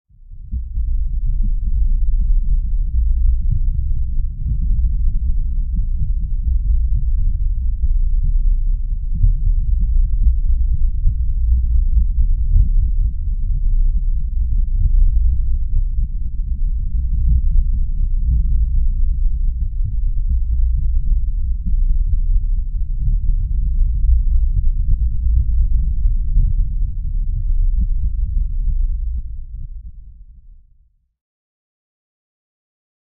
На этой странице собраны звуки тонущего человека в разных ситуациях: паника, борьба за жизнь, захлебывание водой.
Звук пульсации в глубинах водоема